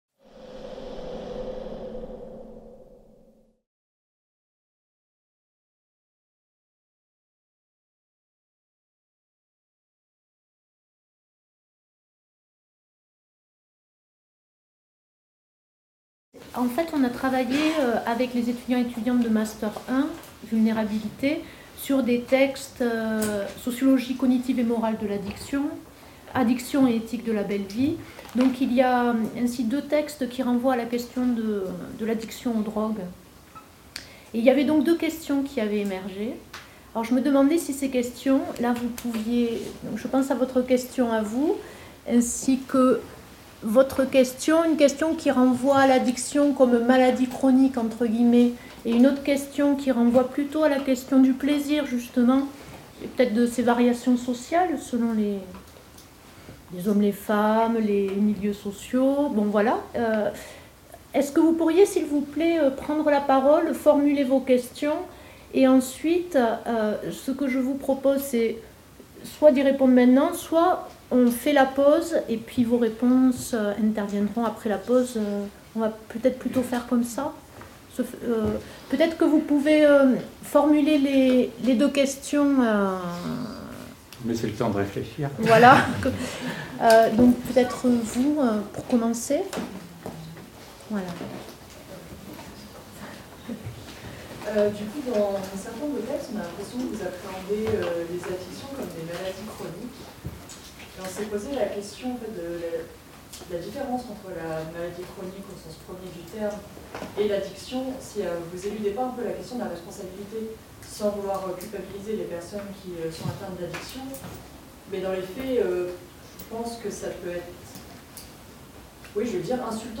(MRSH, Salle des Actes et des Thèses)